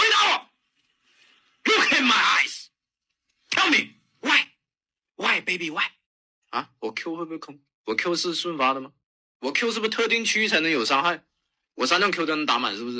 Authoritative AI Safety Warning Voice for Emergency Alerts
Commanding AI Safety Warning Voice
Text-to-Speech
High Intelligibility
Urgent Tone
It offers precise emotional controls, allowing you to switch between a stern warning for hazards and a calm, directive tone for evacuation procedures.
Based on psychoacoustic research for emergency communication, the articulation is sharp and resonant.